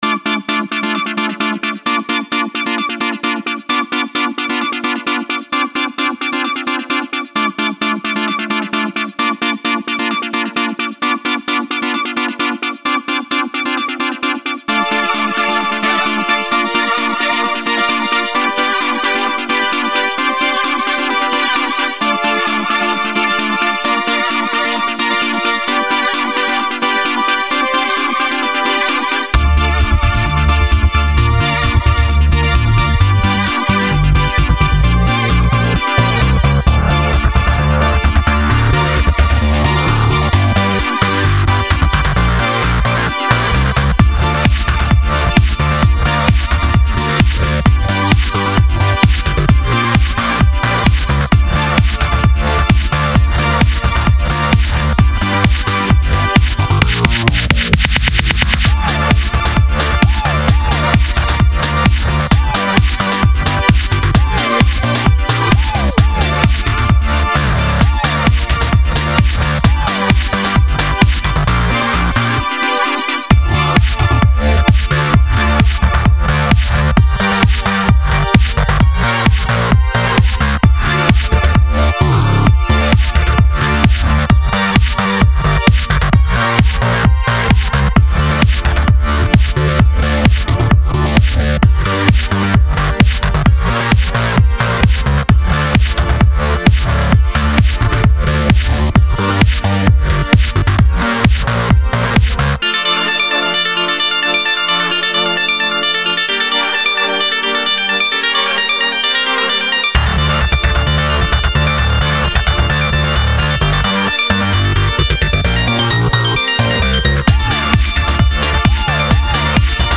Electronic music at its best.